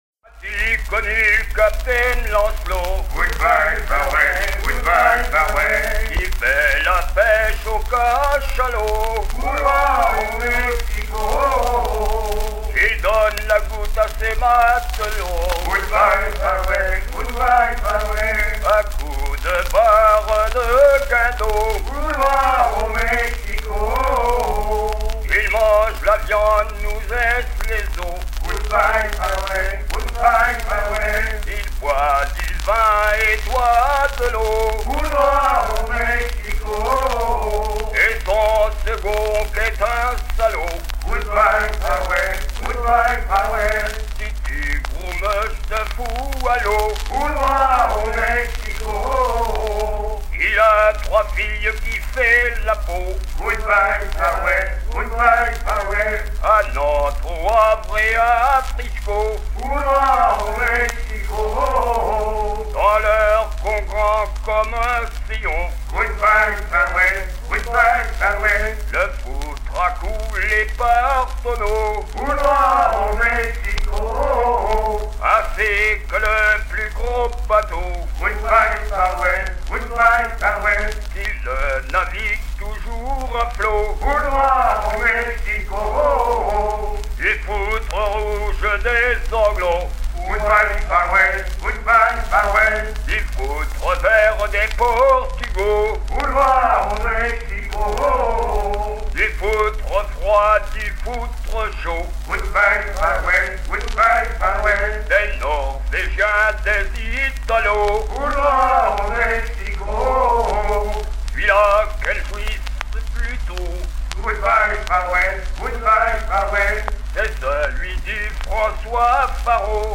lors d'un emission du musée des Arts et traditions populaires
gestuel : à hisser à grands coups
Chansons maritimes
Pièce musicale éditée